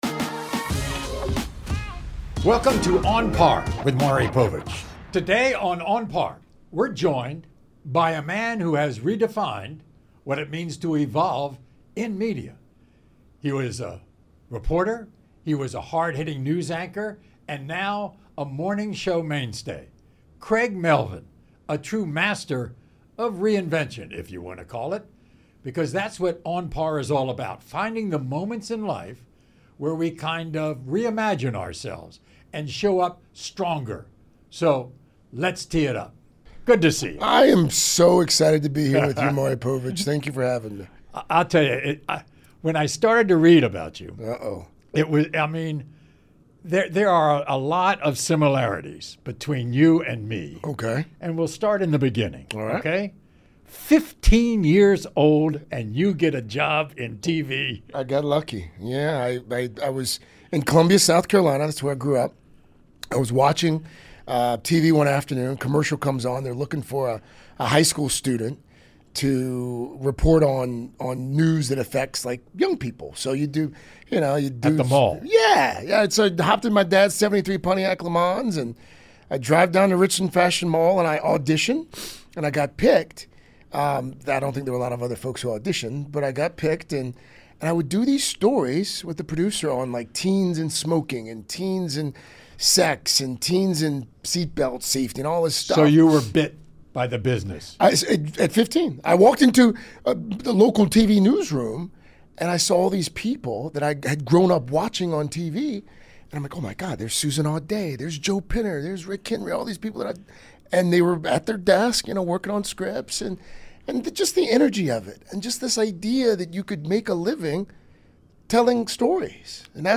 Real talk from two of America’s favorite hosts.